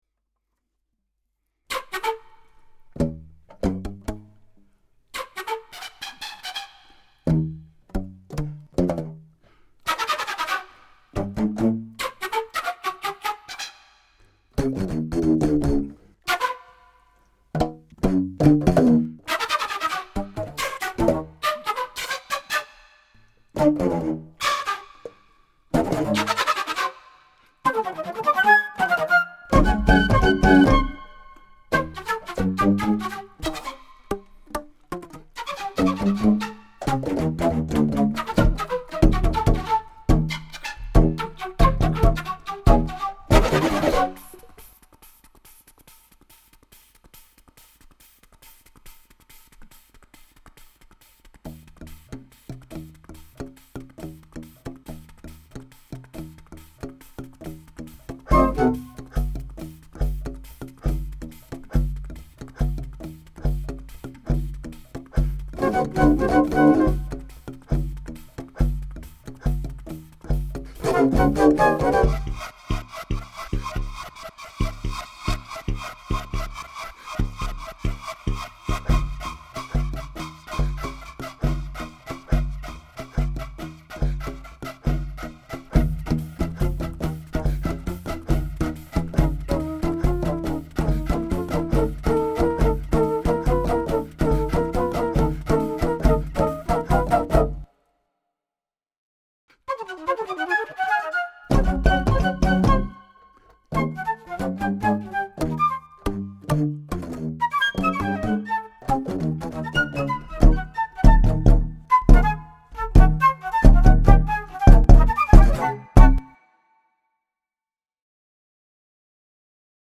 création binaurale 100% flûtes
Juste un break dans la salle adjacente, pour un concert de flûtes binaurales aux casques, mélange de live et de musique préenregistrée.
Une expérience surprenante, du piccolo à la flûte octobasse.